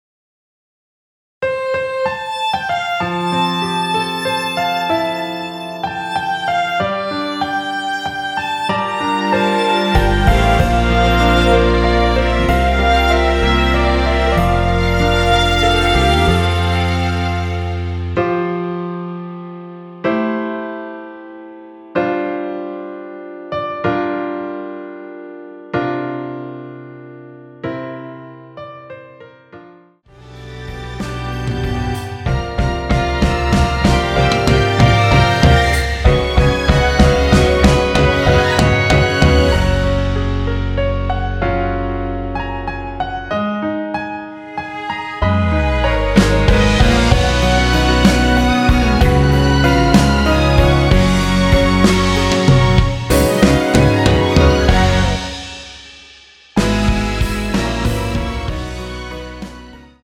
원키에서(+6)올린 MR입니다.
앞부분30초, 뒷부분30초씩 편집해서 올려 드리고 있습니다.
중간에 음이 끈어지고 다시 나오는 이유는